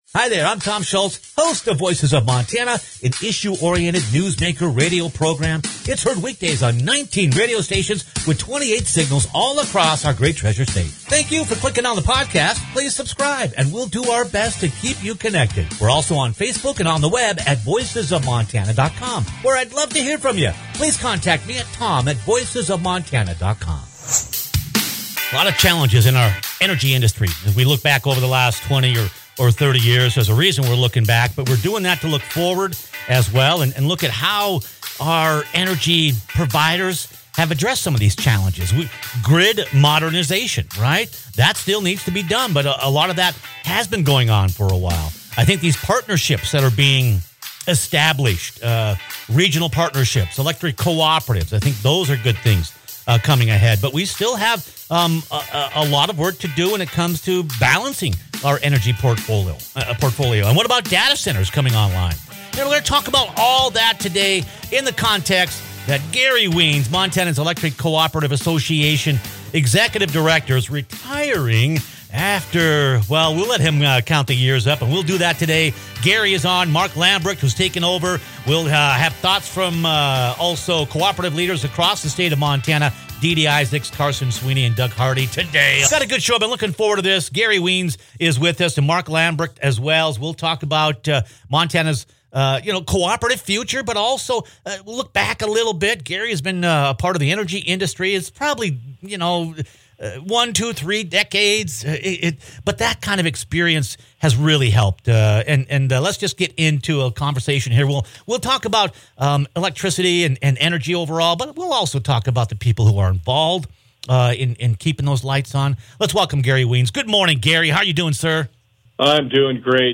Click on the podcast for an intriguing conversation with two leaders shaping the future of rural power